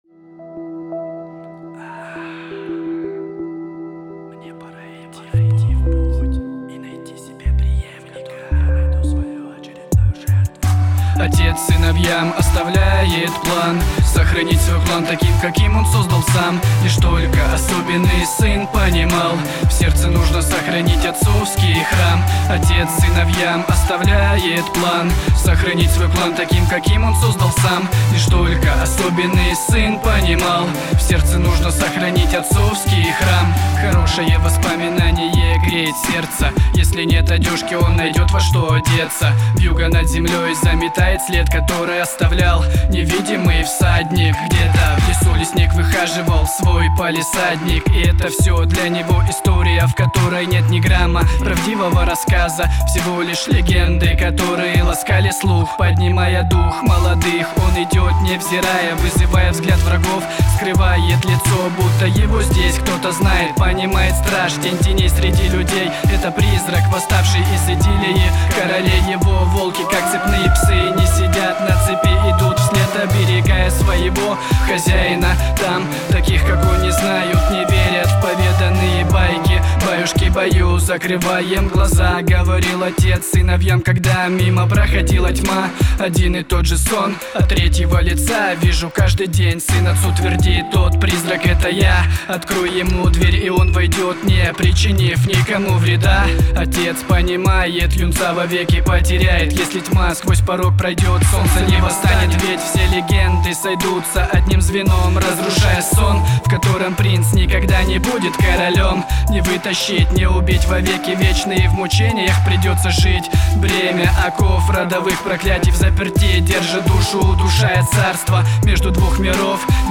Рэп Хип хоп